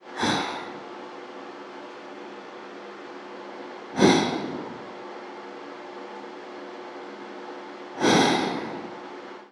나도_모르게_한숨.mp3